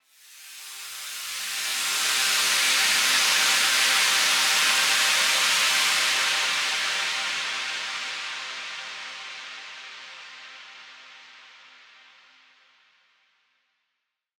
SaS_HiFilterPad03-C.wav